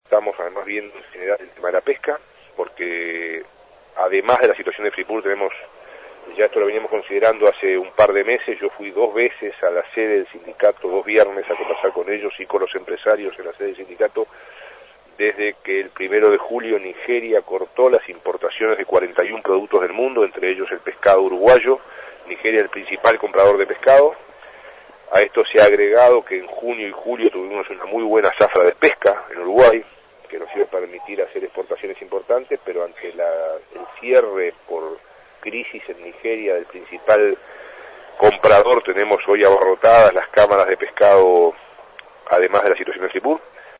En conferencia de prensa, tras el Consejo de Ministros, Murro aseguró que el gobierno está analizando la situación, porque en ambos casos grandes empresas internacionales decidieron, de forma unilateral, irse del país.